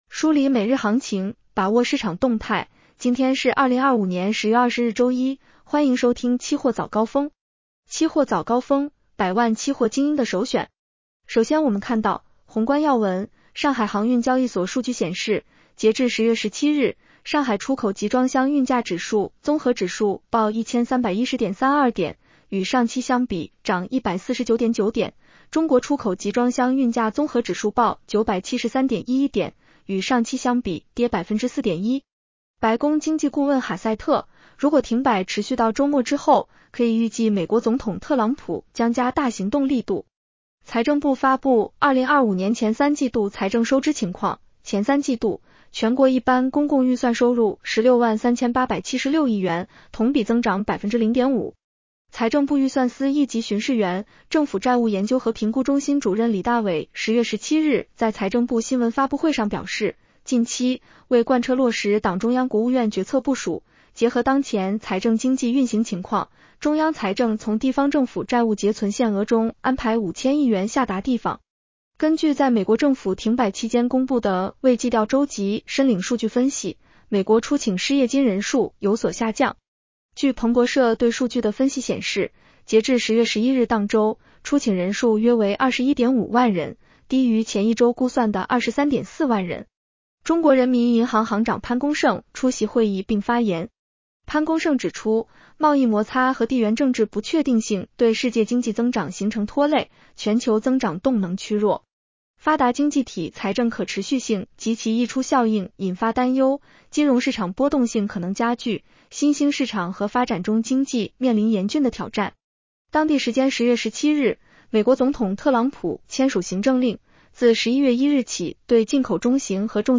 期货早高峰-音频版
期货早高峰-音频版 女声普通话版 下载mp3 热点导读 1.